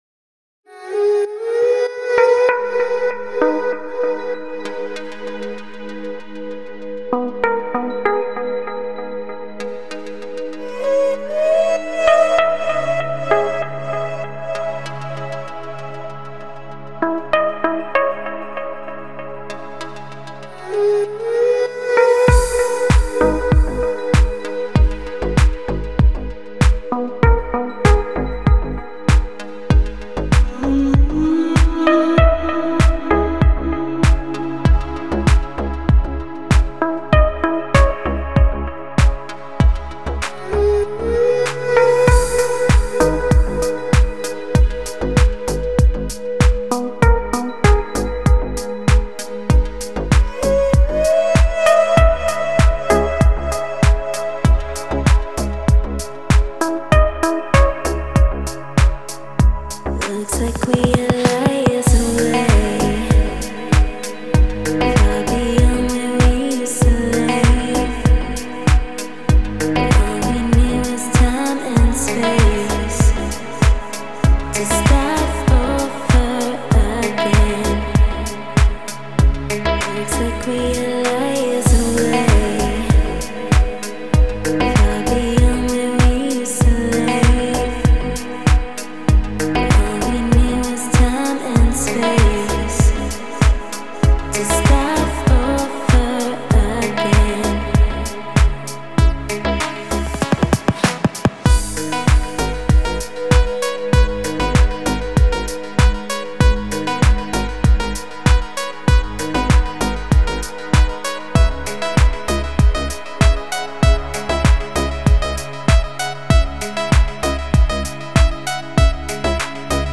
Спокойная музыка
лёгкая музыка
спокойные треки